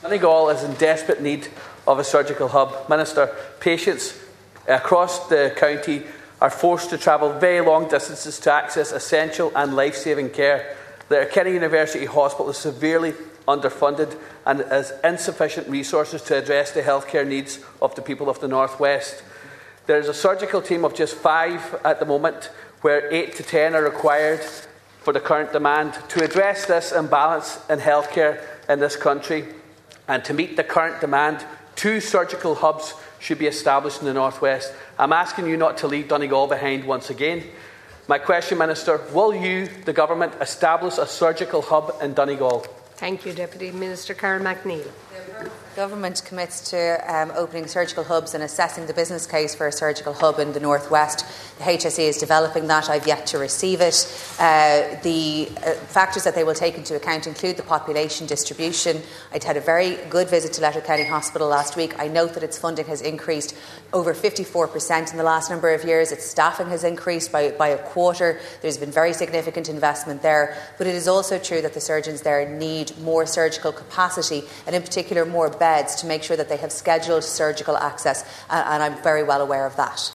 The issue was raised this afternoon by Deputy Charles Ward, on foot of a visit to the hospital last week by Health Minister Jennifer Carroll McNeill.